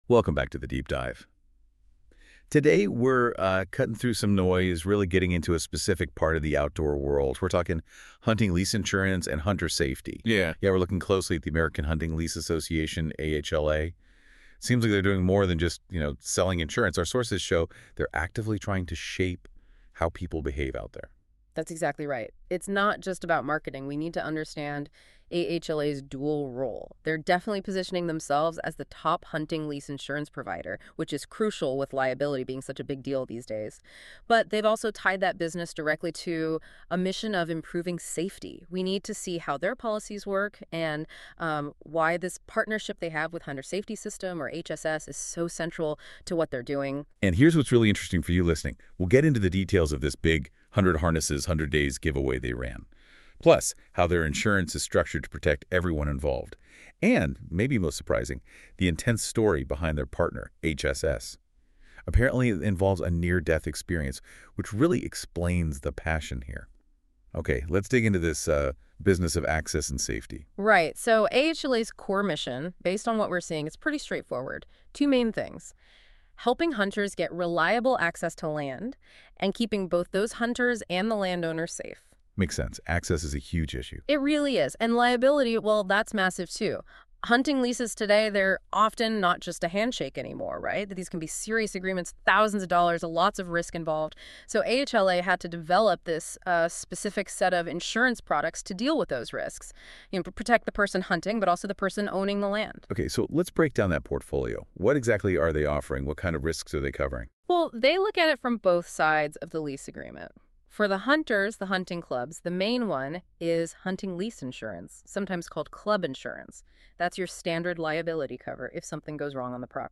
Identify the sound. Audio summary: